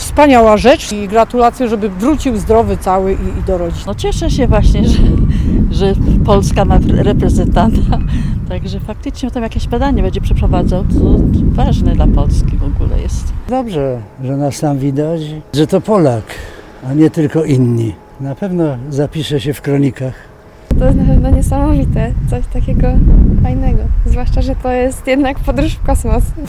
Tagi: Polak ISS Międzynarodowa Stacja Kosmiczna Sławosz Uznański-Wiśniewski Tarnów sonda kosmos